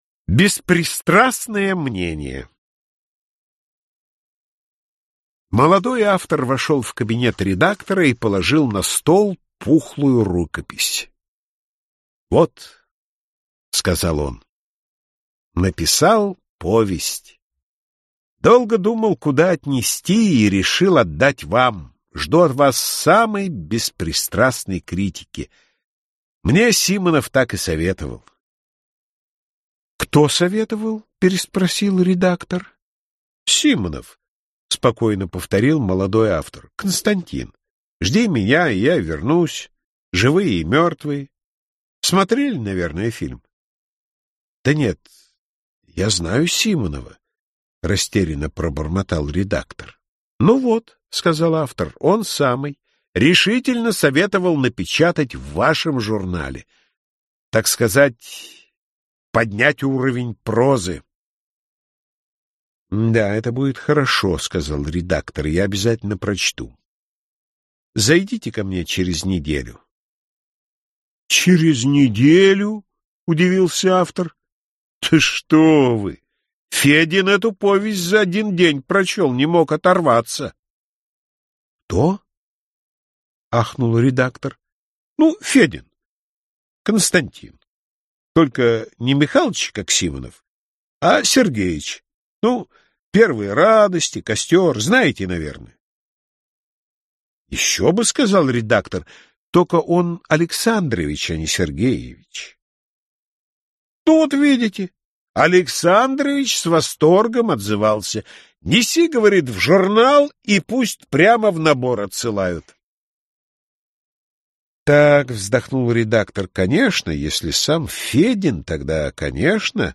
Аудиокнига Юмористические рассказы | Библиотека аудиокниг